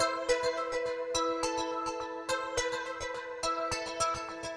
标签： 105 bpm Trap Loops Synth Loops 787.67 KB wav Key : C
声道立体声